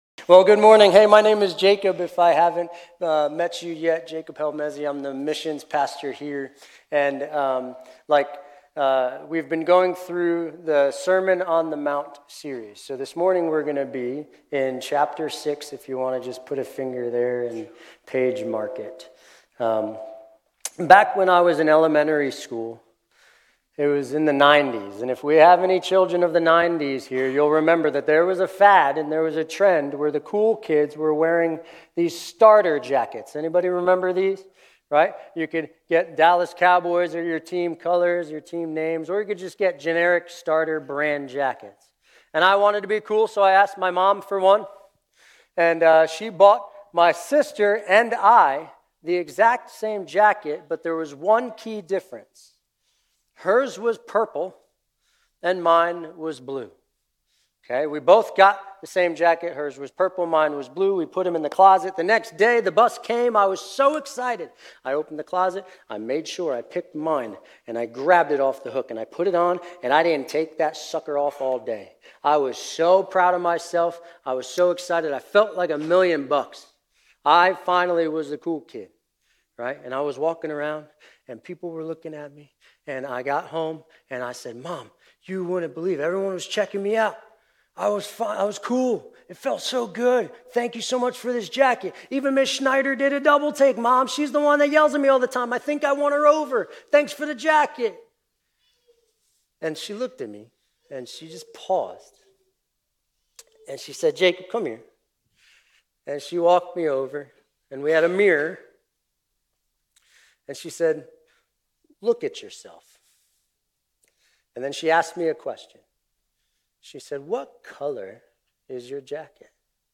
Grace Community Church Old Jacksonville Campus Sermons 3_16 Old Jacksonville Campus Mar 16 2025 | 00:29:48 1x 00:00 / 00:29:48 Subscribe Share RSS Feed Share Link Embed